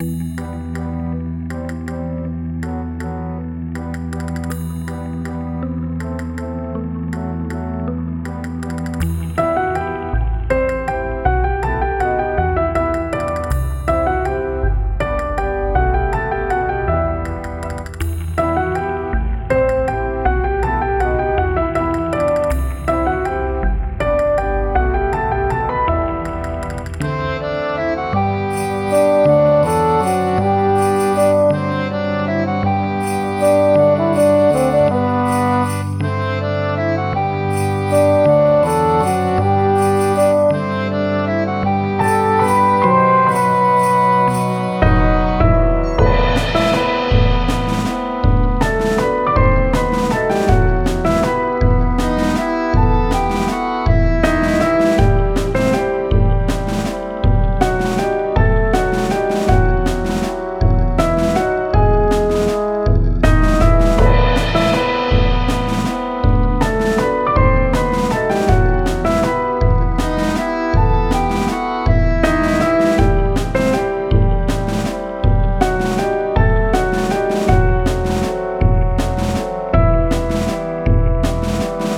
暗い楽曲
【イメージ】悲しいワルツ など